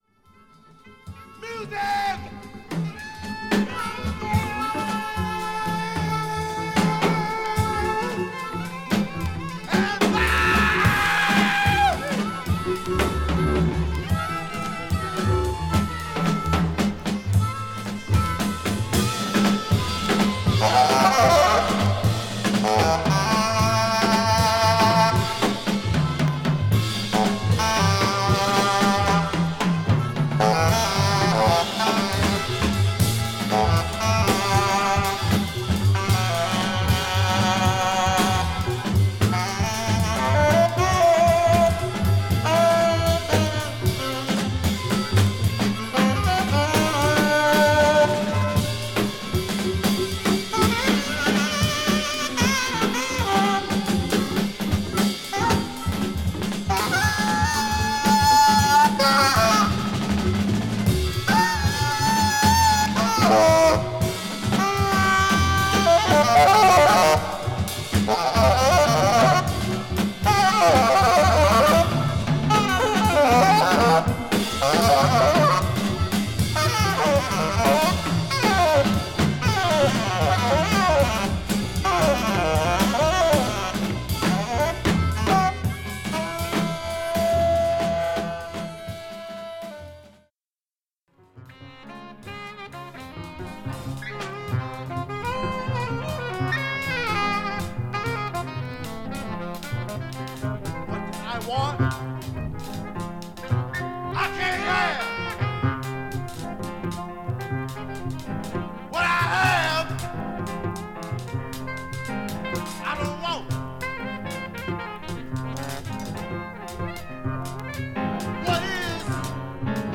JAZZ
69年フランスでのライブ盤も一緒にどうぞ！！！
少しビニール焼あります。